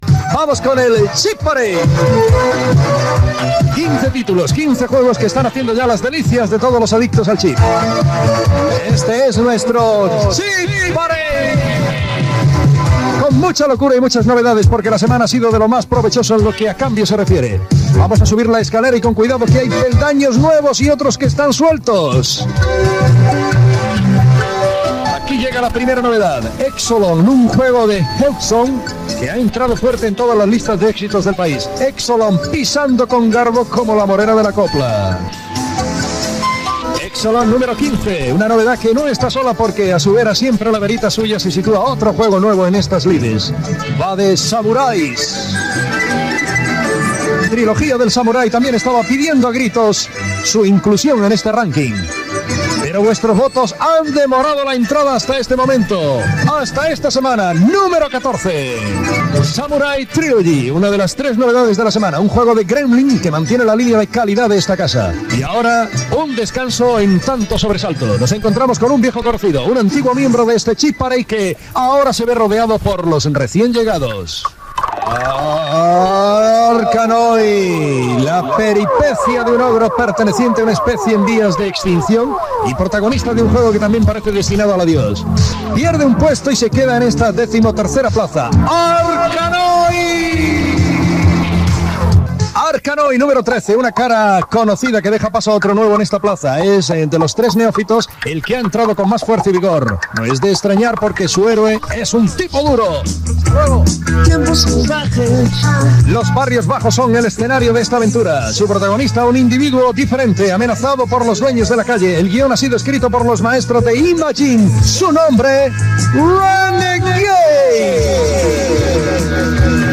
Careta del programa, presentació, equip, telèfon del programa i jocs participatius amb l'audiència (amb la veu de "Chipito"), indicatiu, publicitat, promició del programa "Siempre en domingo", indicatiu i notícies informàtiques.
Entreteniment